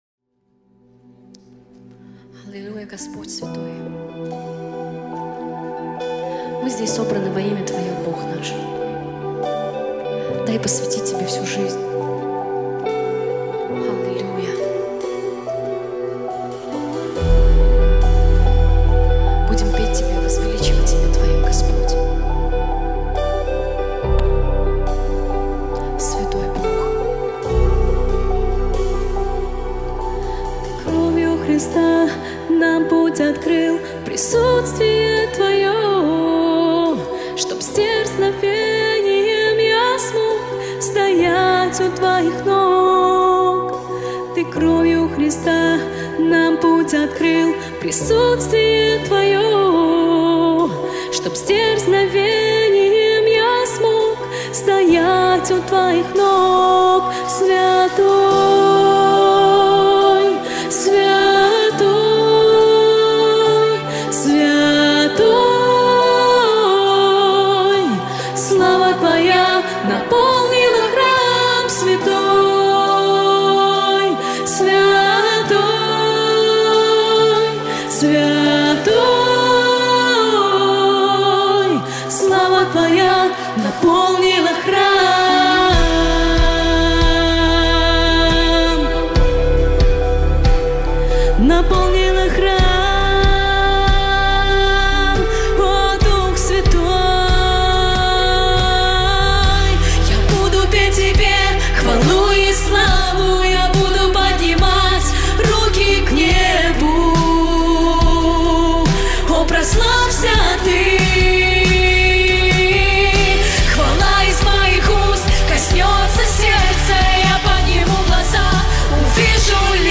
223 просмотра 292 прослушивания 22 скачивания BPM: 70